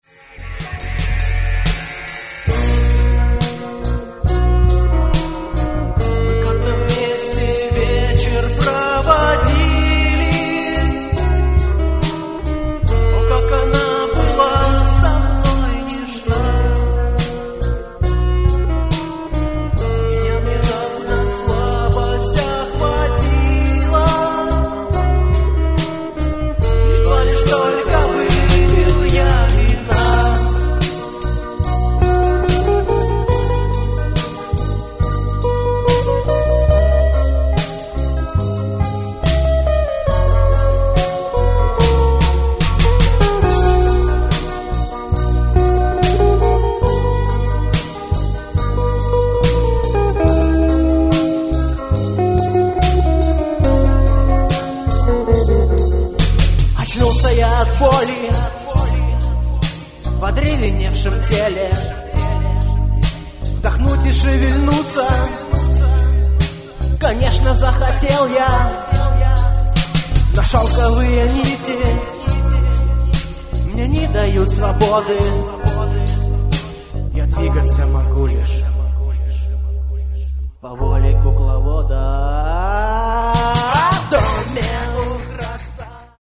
Гитары, бас, вокал
Барабаны
фрагмент (506 k) - mono, 48 kbps, 44 kHz